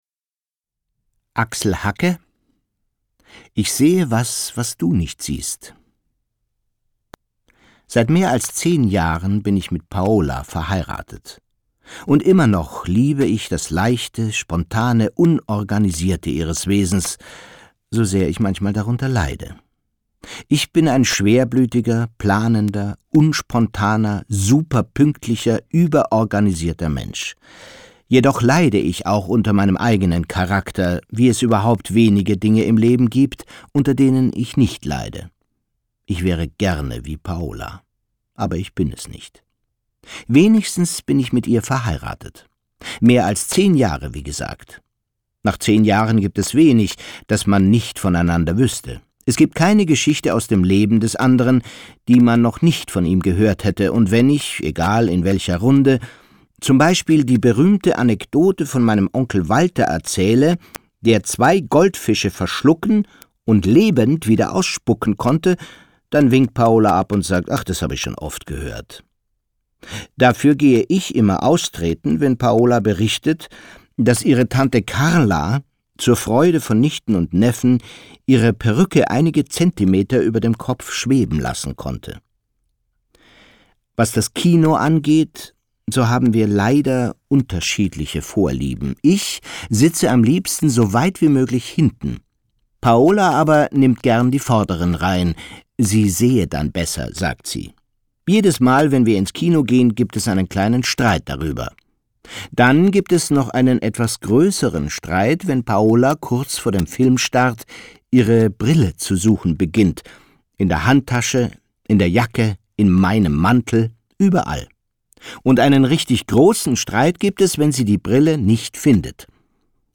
Felix von Manteuffel, Leslie Malton (Sprecher)